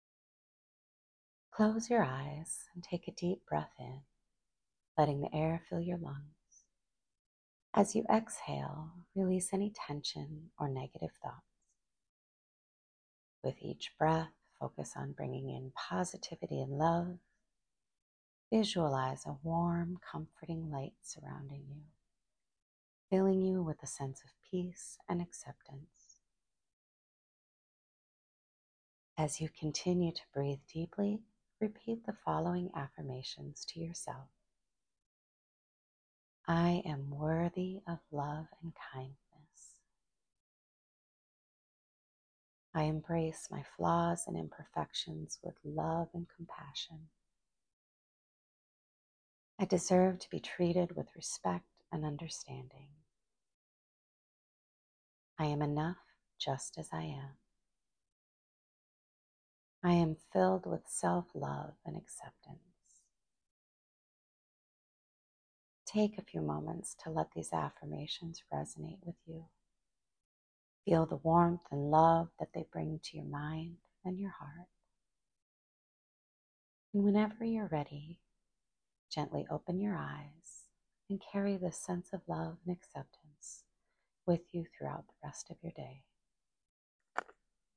Meditation for Self Love.m4a